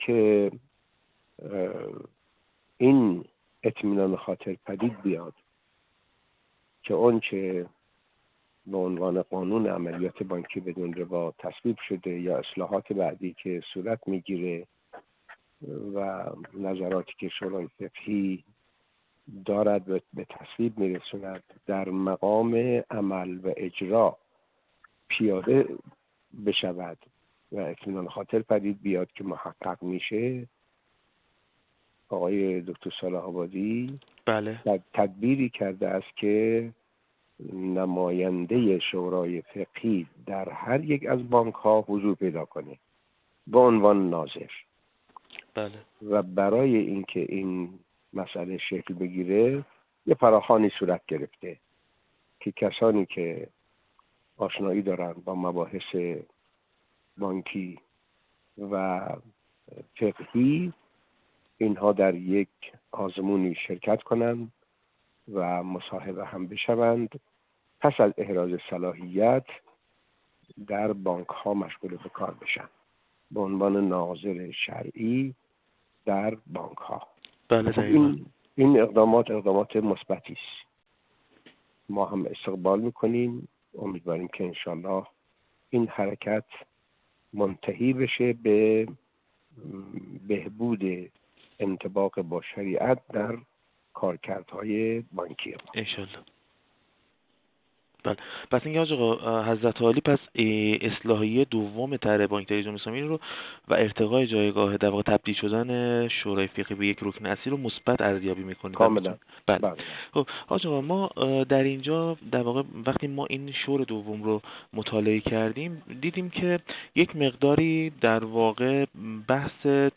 حجت‌الاسلام و المسلمین غلامرضا مصباحی‌مقدم، رئیس شورای فقهی بانک مرکزی جمهوری اسلامی ایران در گفت‌وگو با ایکنا، با مثبت خواندن اصلاحیه دوم طرح بانک مرکزی جمهوری اسلامی ایران و تبدیل شورای فقهی به رکن اصلی بانک مرکزی گفت: شورای فقهی بانک مرکزی جمهوری اسلامی ایران به دنبال تصویب برنامه ششم توسعه در سال ۱۳۹۷ شکل گرفته و ماده ۱۶ این برنامه به موضوع شورای فقهی اختصاص پیدا کرده است.
گفت‌وگو